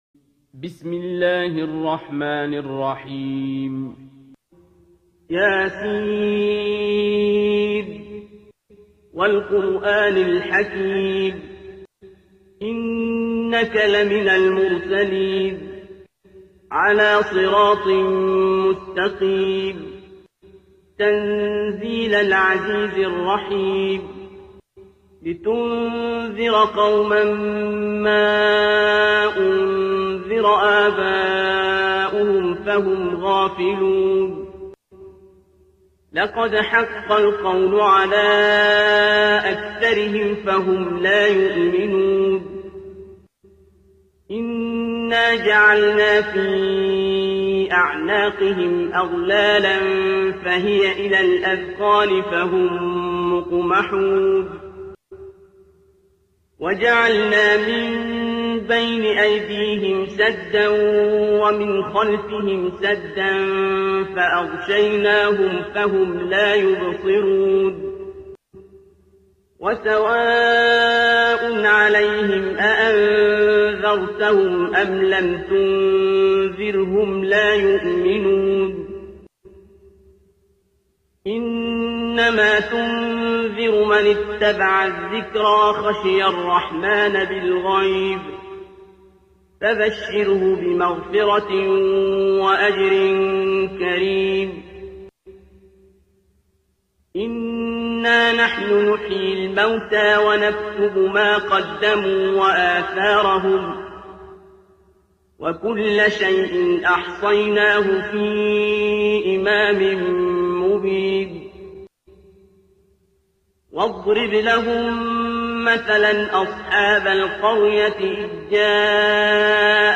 ترتیل سوره یس با صدای عبدالباسط عبدالصمد